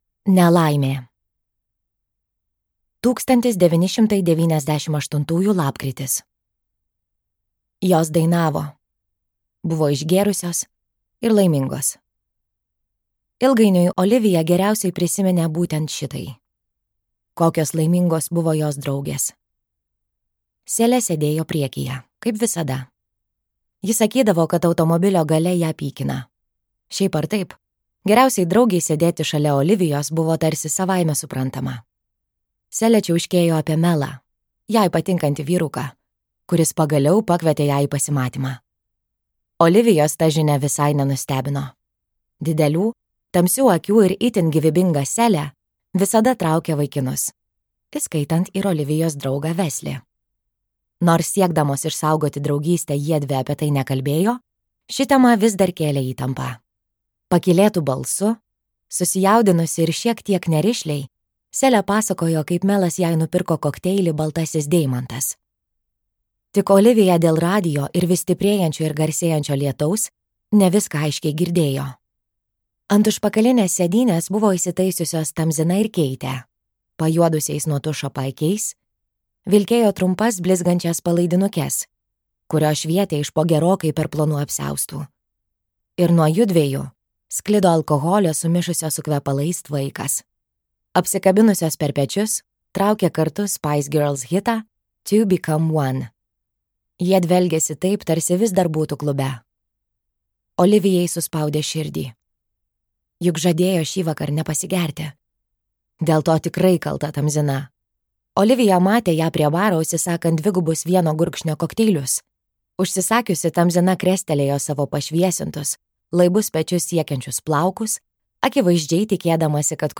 Dingusios merginos | Audioknygos | baltos lankos